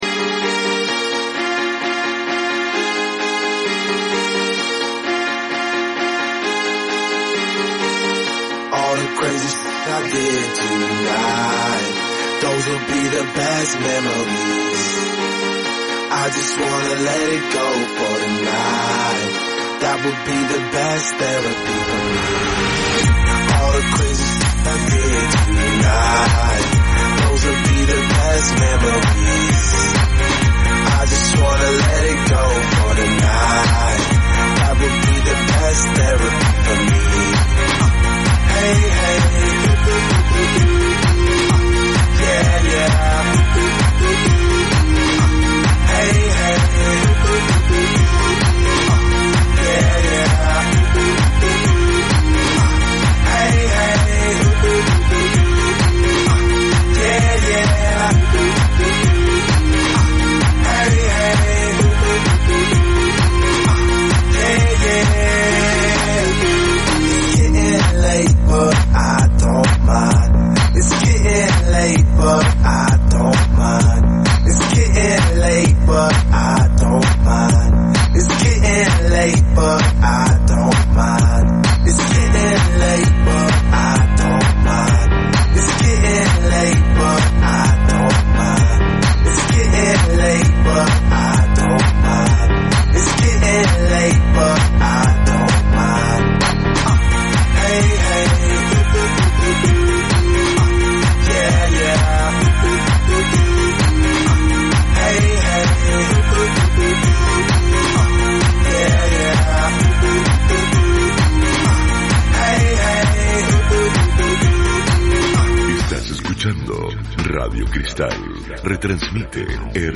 Además, entrevistamos